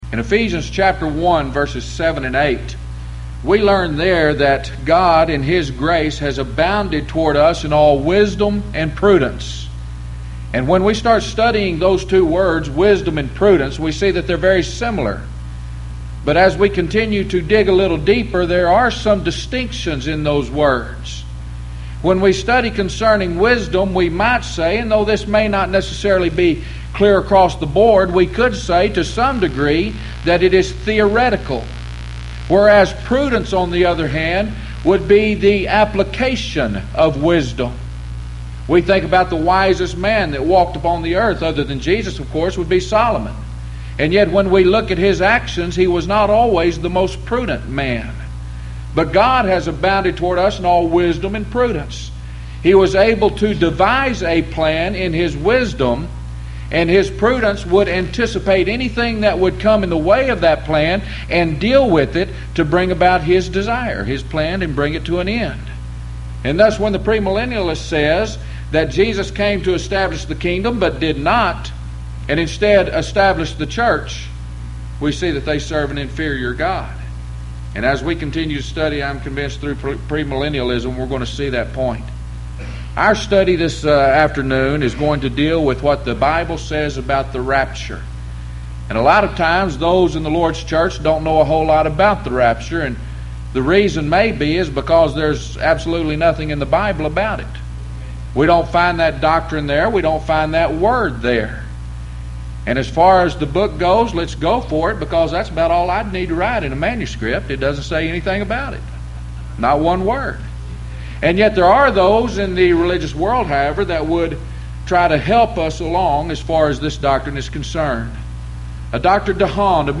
Series: Houston College of the Bible Lectures Event: 1997 HCB Lectures